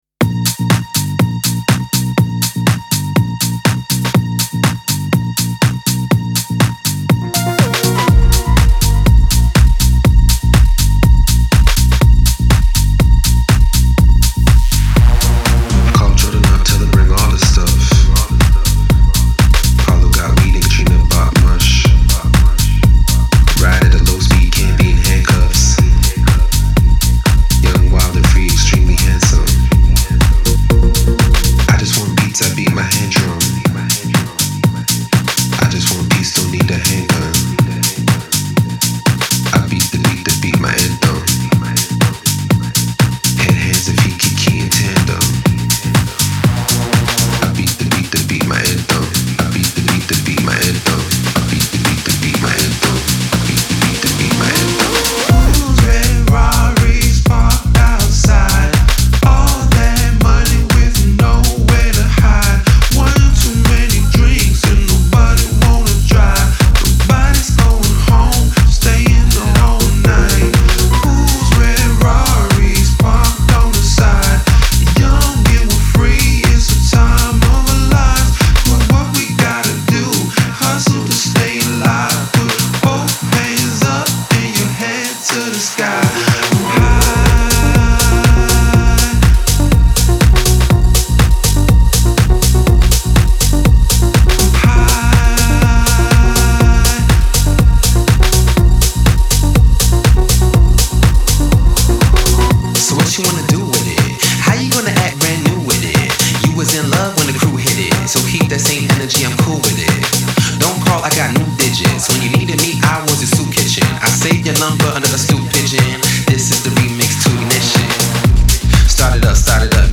• Жанр: Techno, House